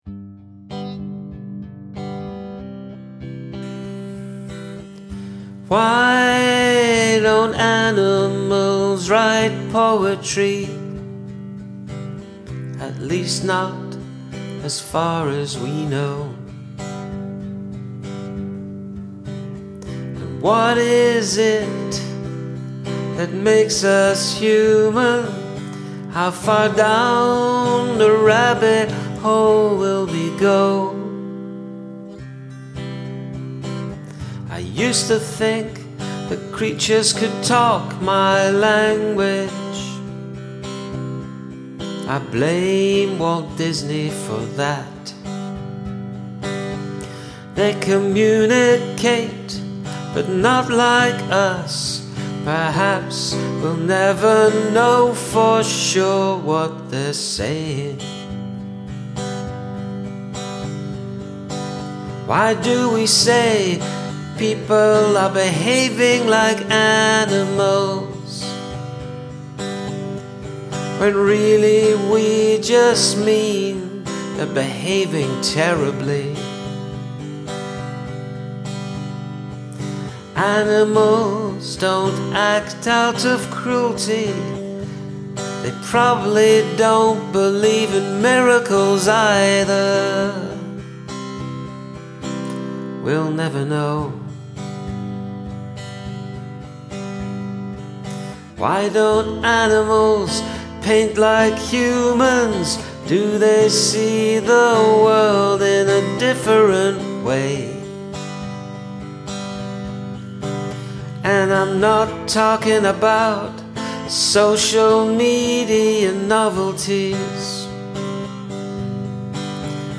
Sweet performance almost lullabyish.
you'd think they would, because everything they say rhymes. a whimsical song. very nice.
This is very Beatles-y, love your style